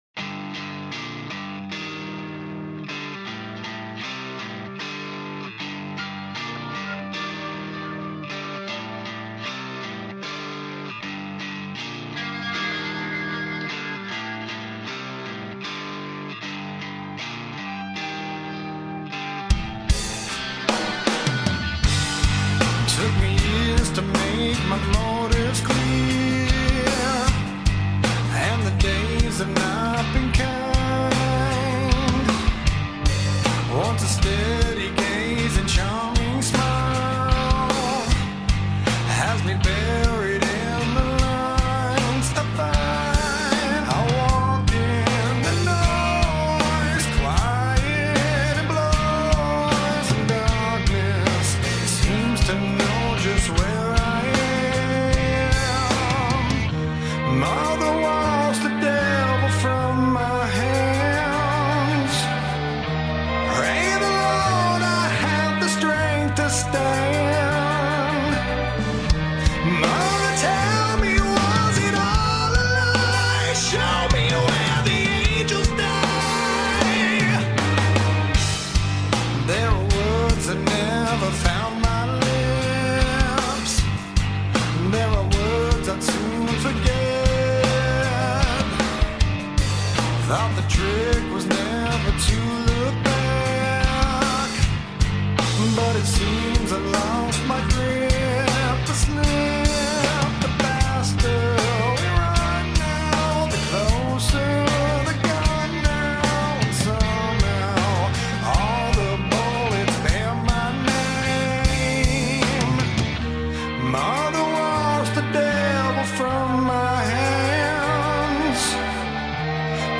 Metal Rock
متال راک